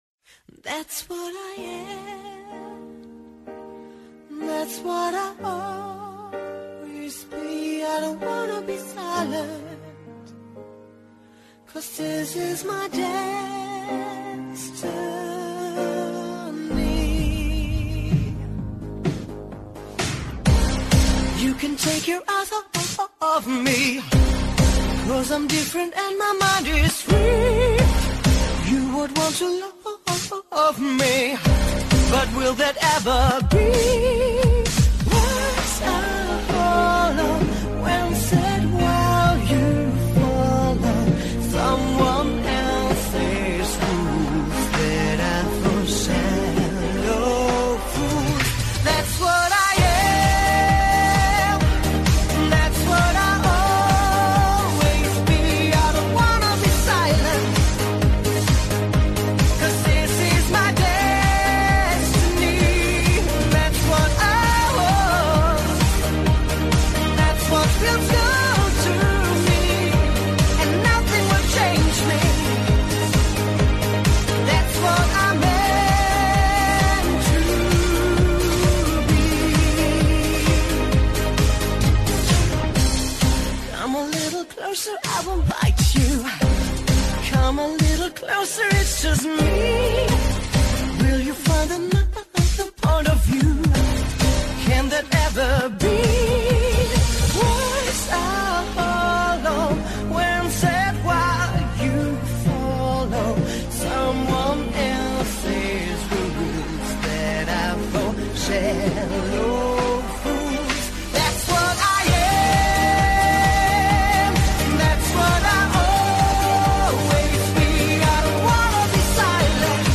The June show is all over the musical map...another two hours, including 31 songs by 21 artists, with 9 new to OutRadio.